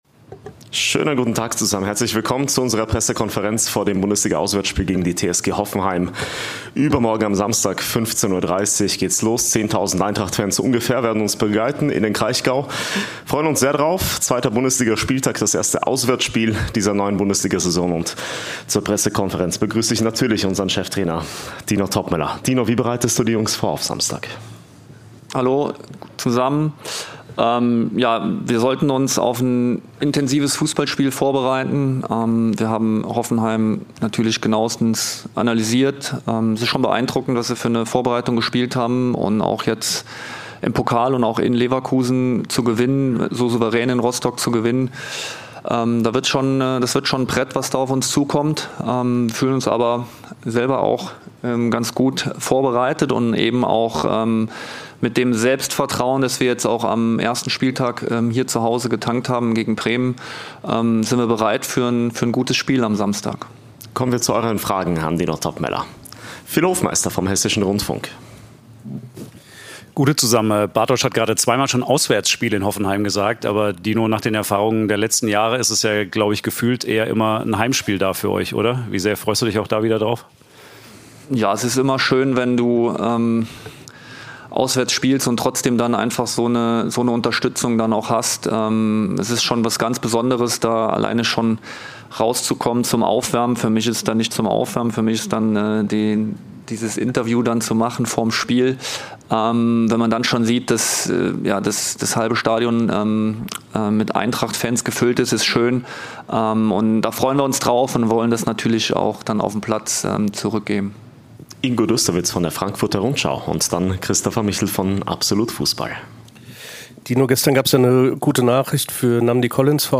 Die Pressekonferenz vor unserem Bundesliga-Auswärtsspiel in Sinsheim mit Cheftrainer Dino Toppmöller